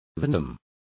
Complete with pronunciation of the translation of venom.